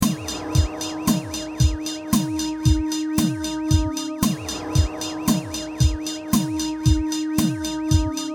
Loop (3), 131 KB
tb_loop_3.mp3